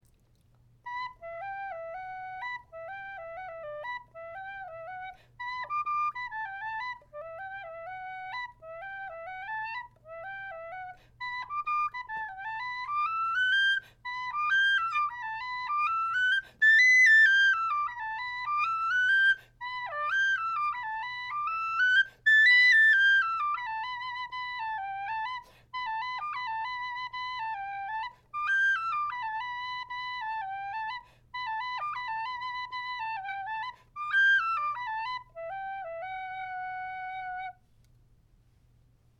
Number: #7 Key: High D Date completed: September 2024 Type: A telescoping brass high D model with brass head and black plastic fipple plug.
Volume: This whistle is louder than most MackBeths but still retains a generally pure tone.